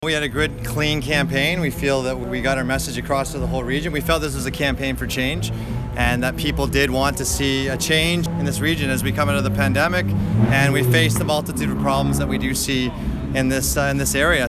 Ryan Williams made the statement very early Tuesday morning.